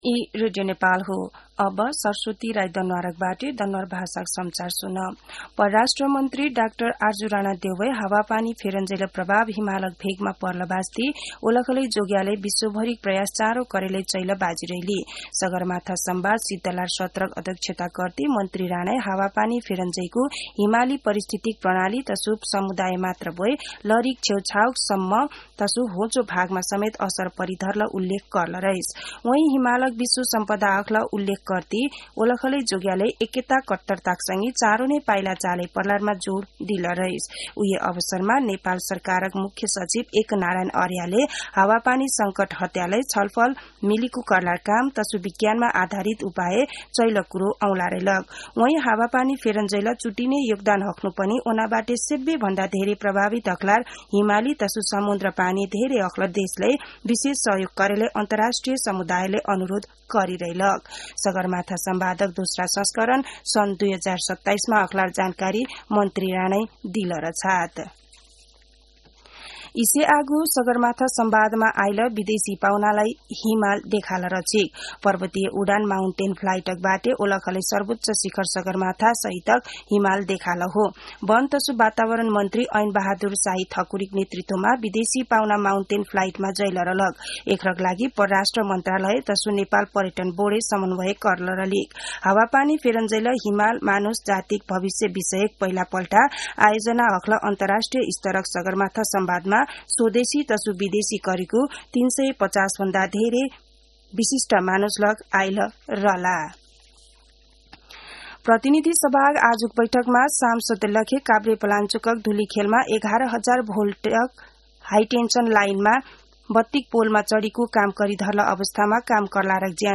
दनुवार भाषामा समाचार : ४ जेठ , २०८२
danuwar-news-1-2.mp3